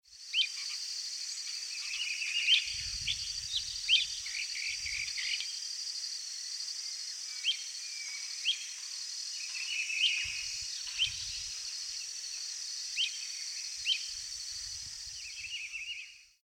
Bran-colored Flycatcher (Myiophobus fasciatus)
Life Stage: Adult
Location or protected area: Delta del Paraná
Condition: Wild
Certainty: Observed, Recorded vocal
mosqueta-estriada.mp3